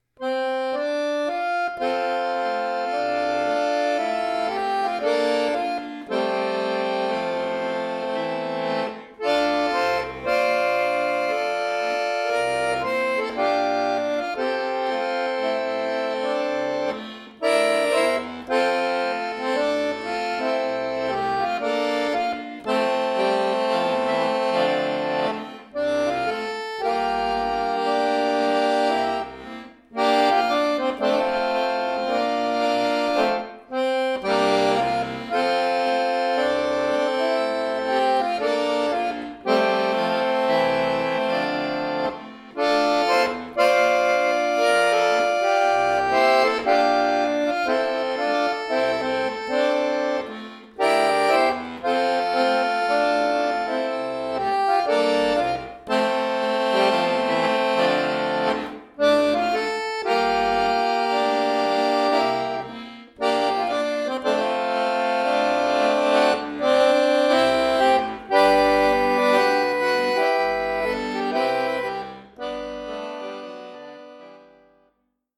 Der Folk-Klassiker
Folksong